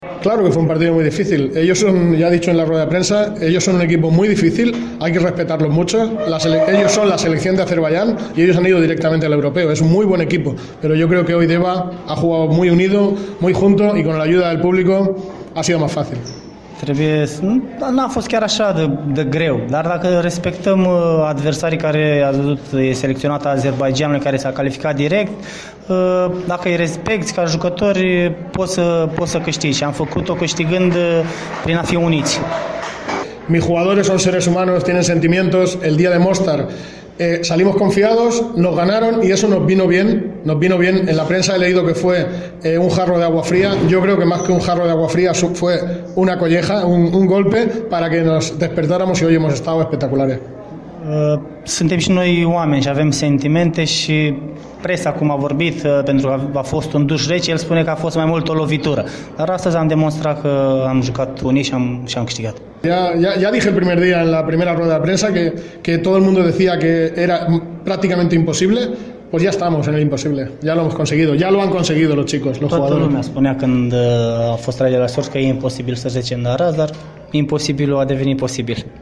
interviuri